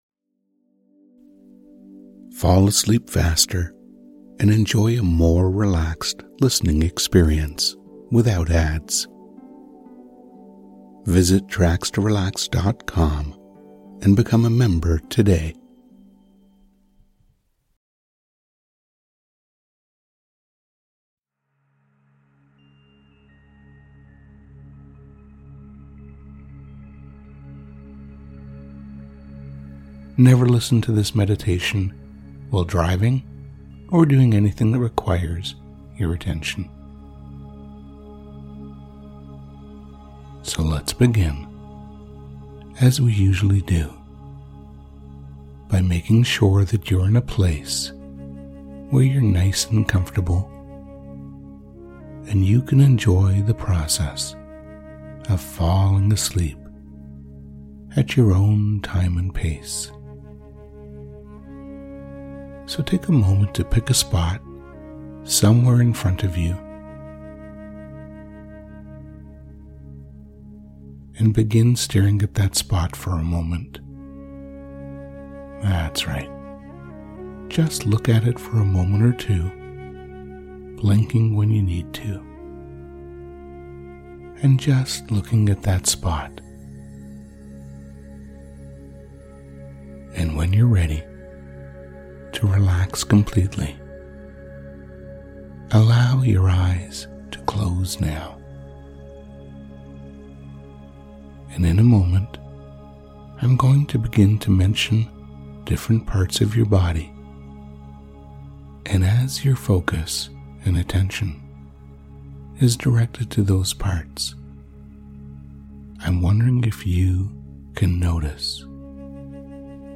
Tracks-To-Relax-Sleep-Meditations-episode-Staircase-To-Relaxation-A-Guided-Sleep-Meditation.mp3